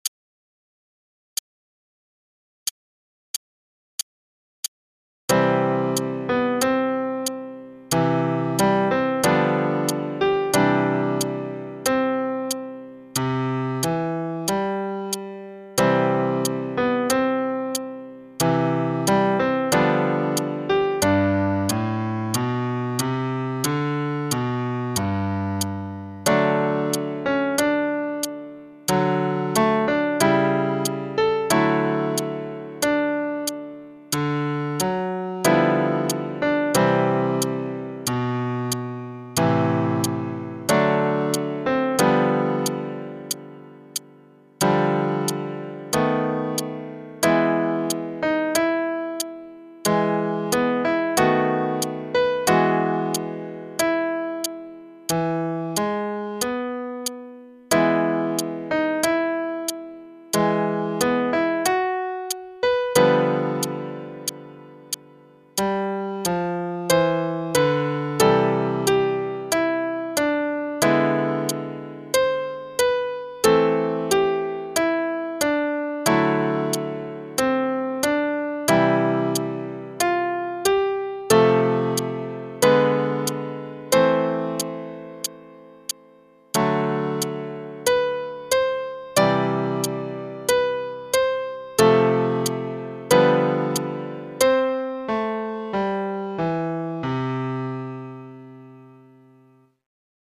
backing track
qn=92,